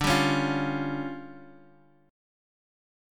Listen to DmM7bb5 strummed